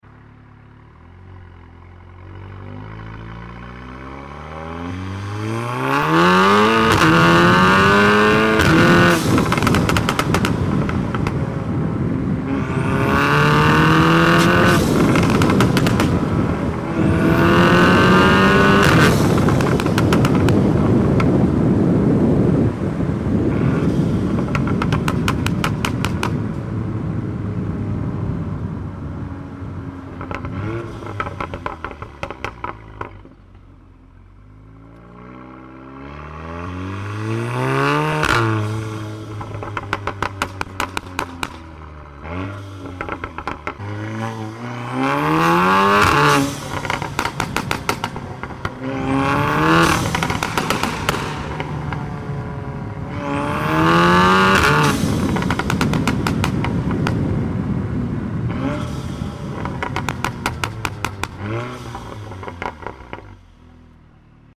Golf R POV Drive 🔥 Sound Effects Free Download